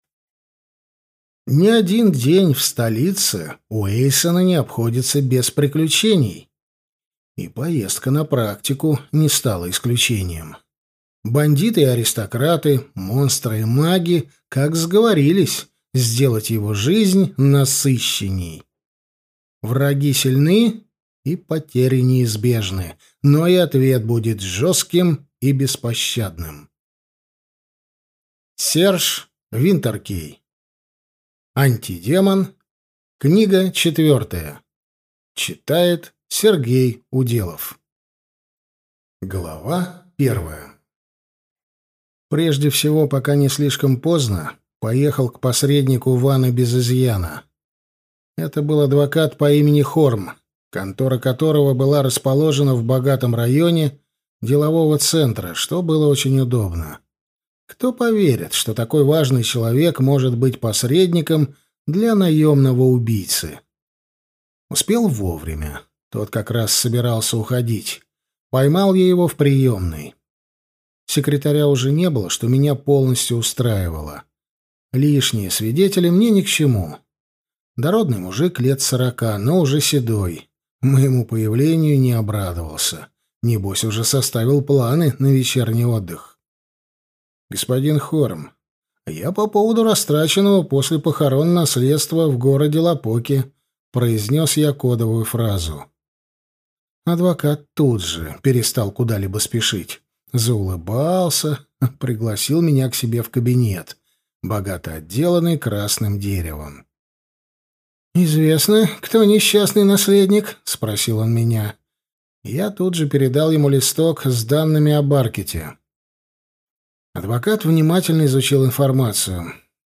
Аудиокнига Антидемон. Книга 4 | Библиотека аудиокниг